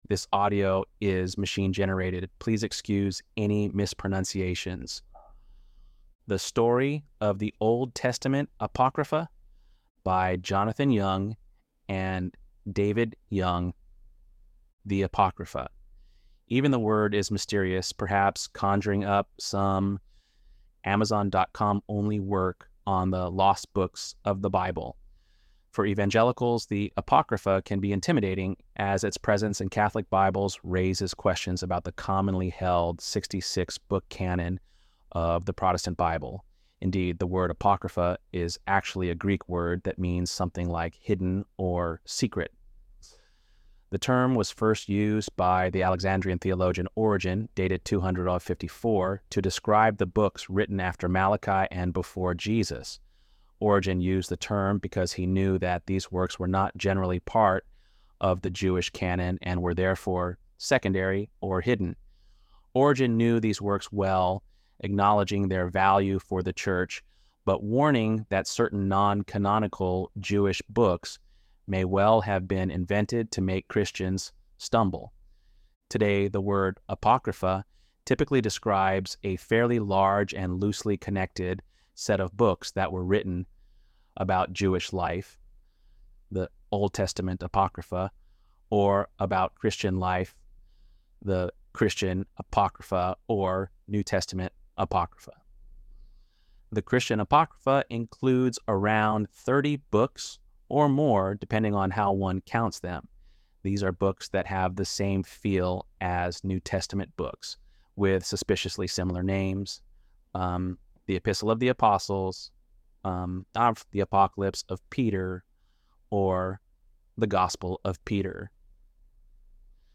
ElevenLabs_7.5_Apocrypha.mp3